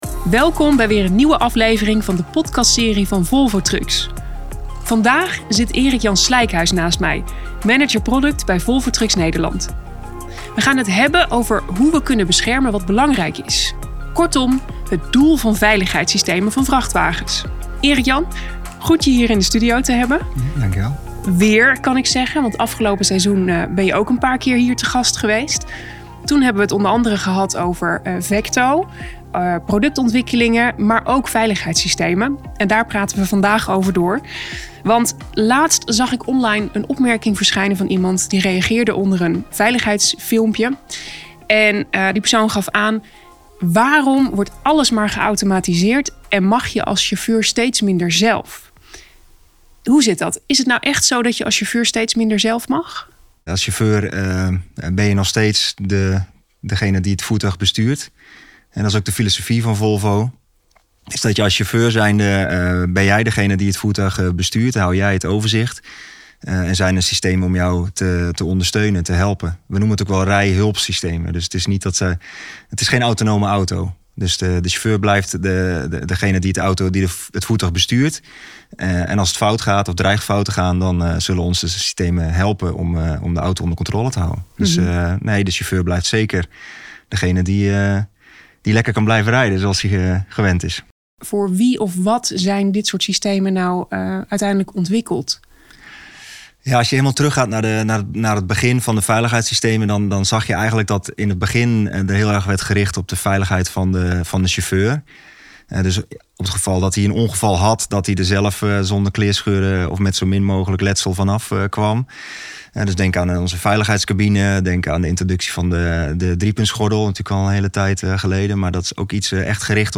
In deze podcastserie gaan we in gesprek met experts over verschillende thema's. Samen gaan we onderweg naar een uitstootvrije toekomst, zonder dodelijke ongevallen in het verkeer en zonder ongeplande stilstand.